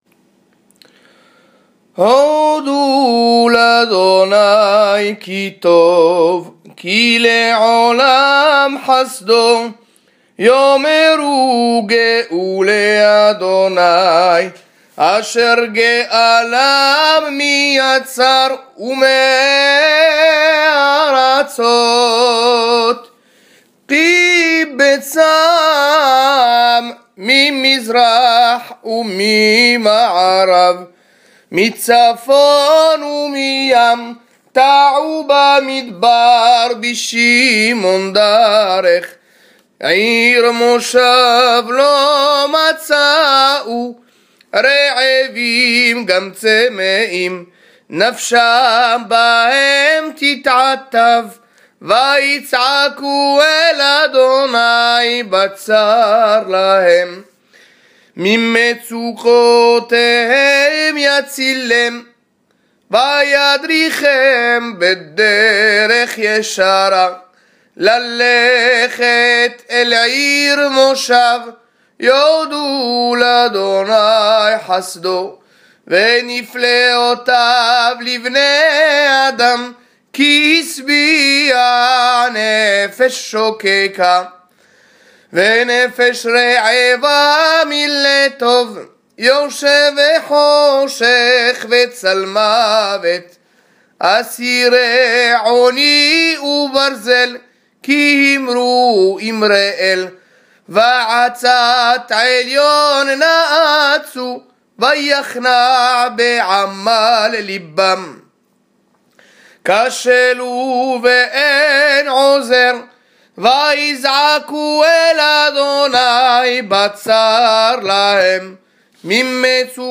Hazanout